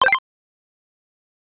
Chiptune Sample Pack
8bit_FX_C_03_02.wav